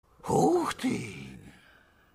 Осел говорит Ух ты